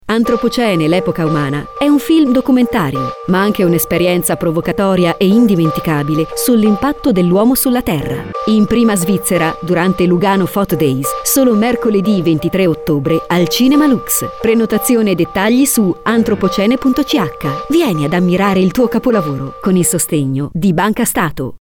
Spot di LuganoPhotoDays 2019 su Radio 3i, con il sostegno di BancaStato.